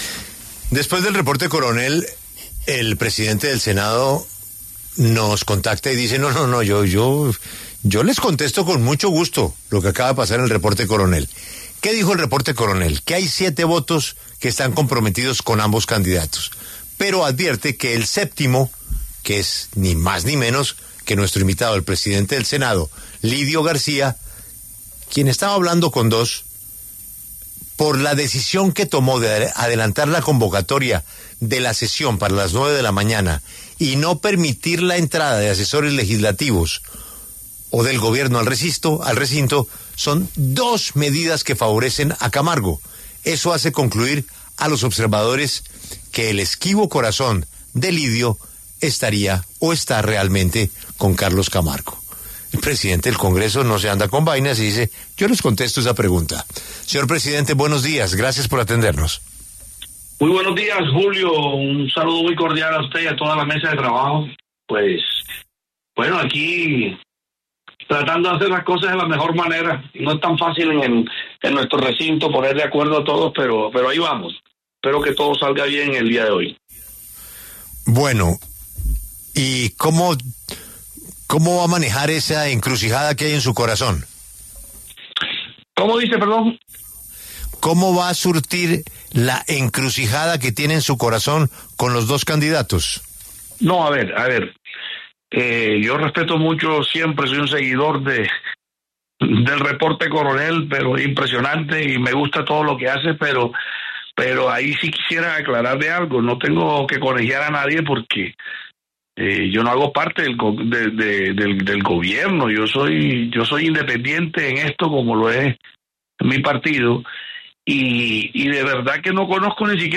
El presidente del Senado, el liberal Lidio García, pasó por los micrófonos de La W. Habló sobre las medidas para la elección del nuevo magistrado de la Corte Constitucional y cantó su voto al aire.